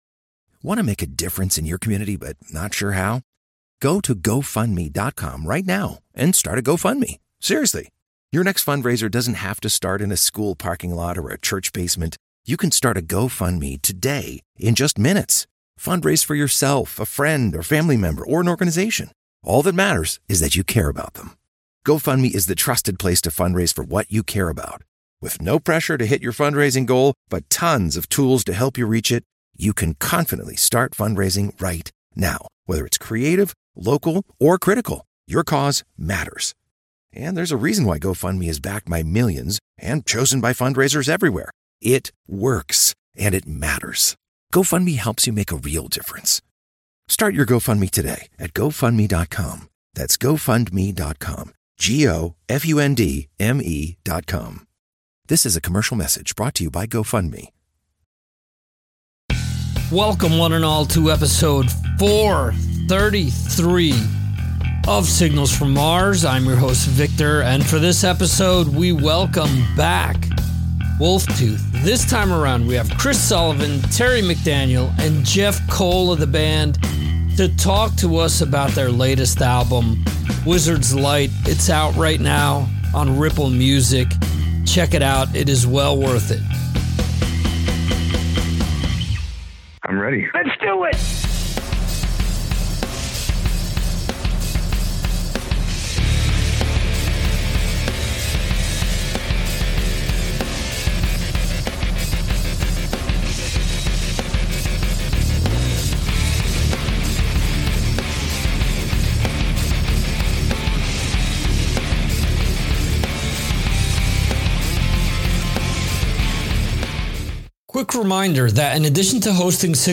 All types of hard rock and metal interviews and music discussions since 2009.